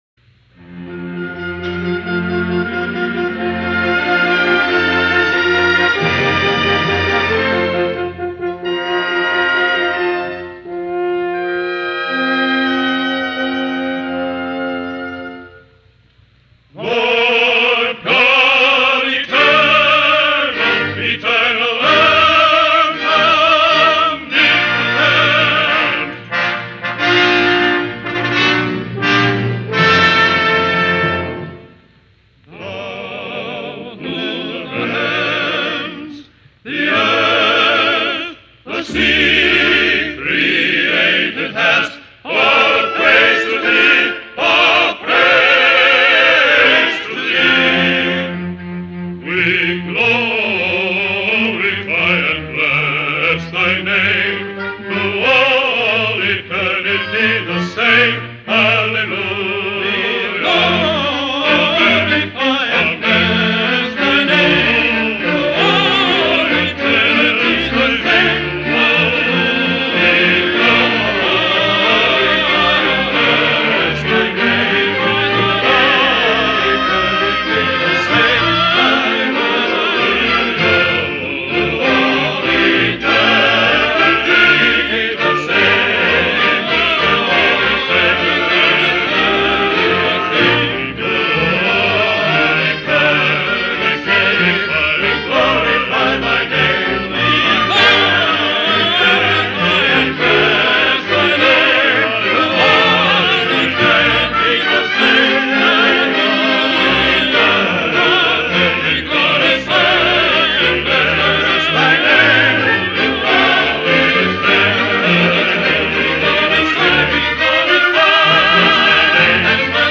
Final Chorus from Voyage Of Columbus